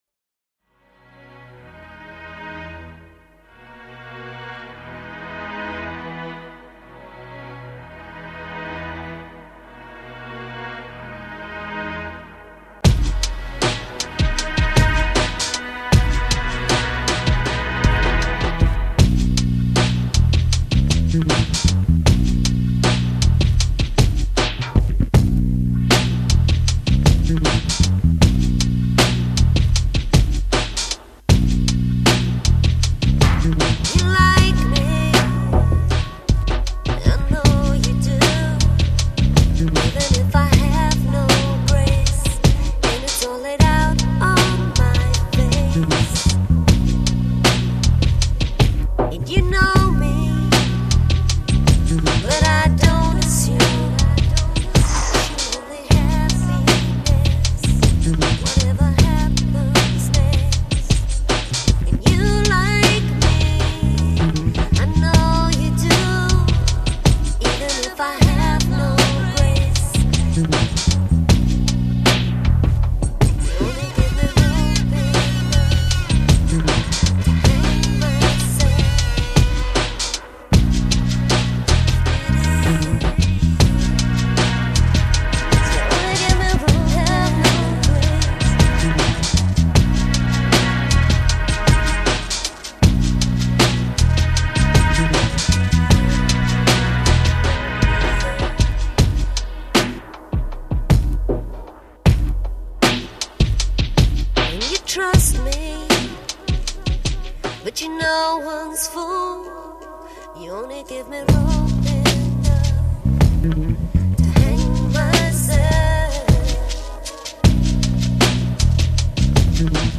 Lo-Fi, ChillOut